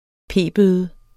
Udtale [ ˈpeˀˌbøːðə ]